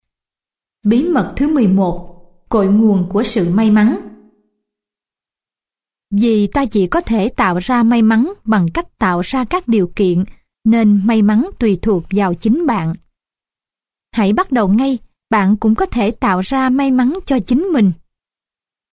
Sách nói | Bí mật của may mắn 13